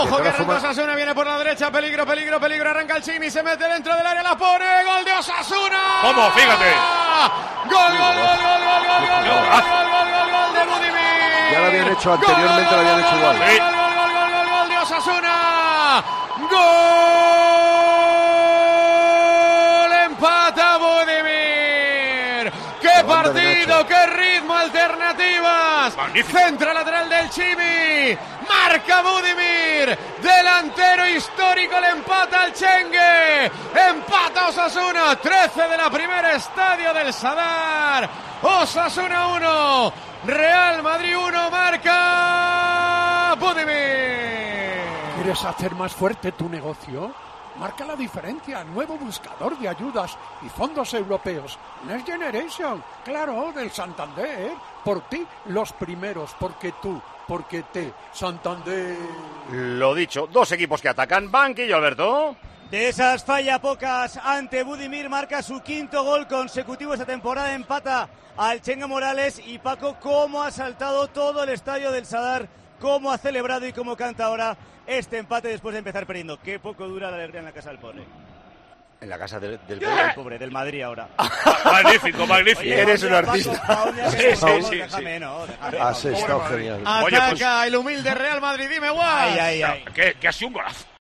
narra el gol de Budimir al Real Madrid